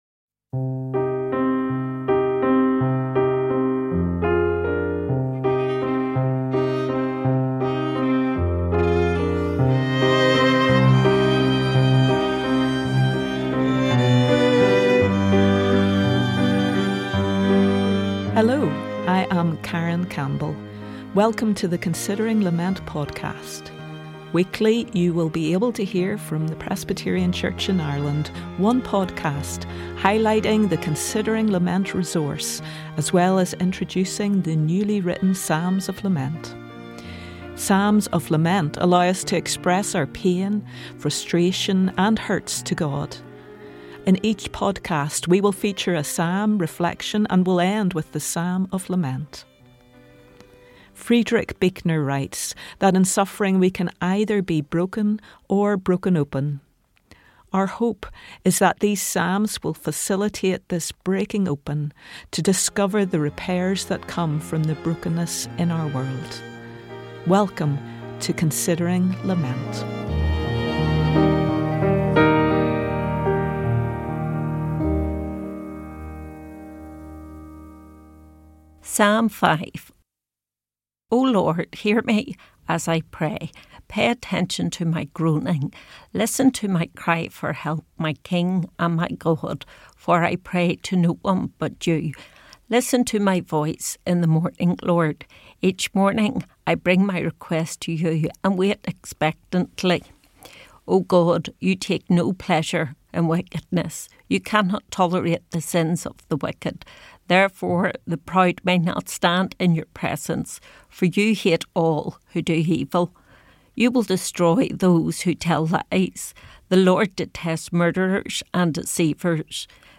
The episode concludes with a newly composed psalm of lament, inspired by Psalm 5, giving voice to contemporary struggles while holding onto hope for healing and restoration. Thoughtful and contemplative, this short podcast invites listeners to pause, reflect, and consider what reconciliation can look like in their own lives.